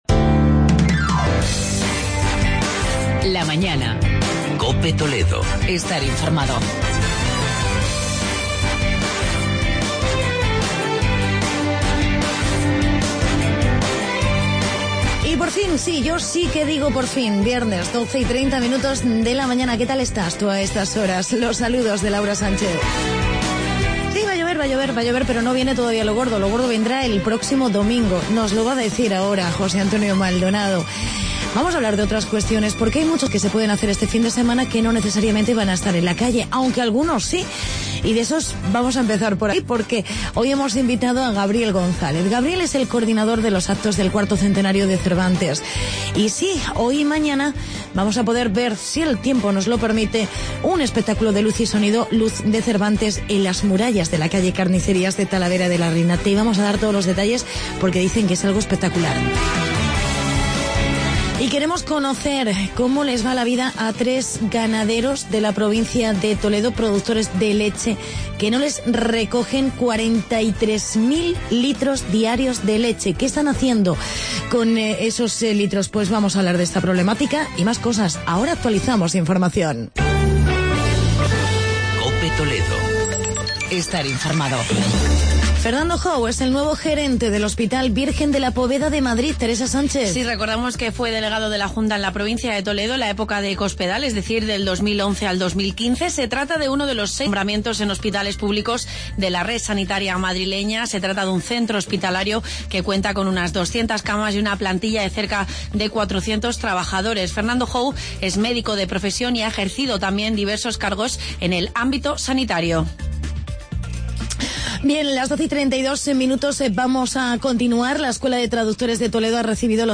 Entrevisa